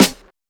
Snares
Osc_Snr.wav